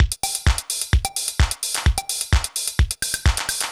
TEC Beat - Mix 1.wav